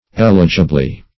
eligibly - definition of eligibly - synonyms, pronunciation, spelling from Free Dictionary
eligibly - definition of eligibly - synonyms, pronunciation, spelling from Free Dictionary Search Result for " eligibly" : The Collaborative International Dictionary of English v.0.48: Eligibly \El"i*gi*bly\, adv. In an eligible manner.